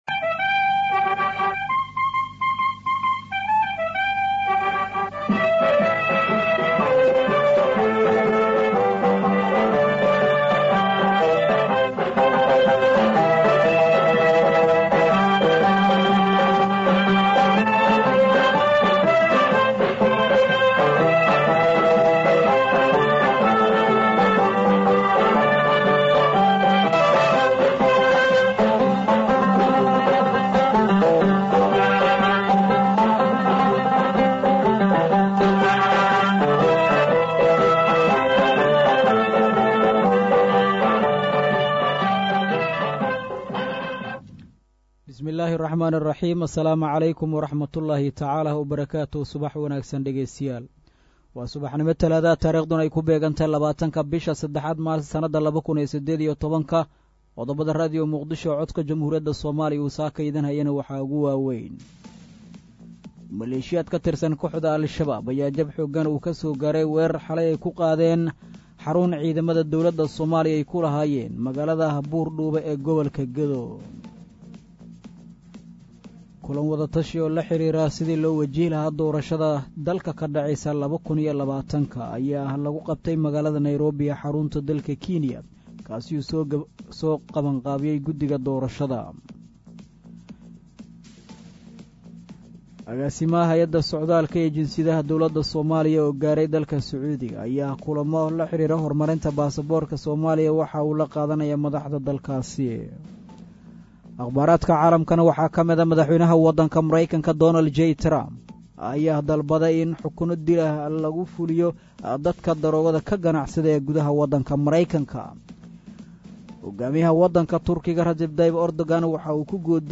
Dhageyso:: Warka Subax ee Radio Muqdisho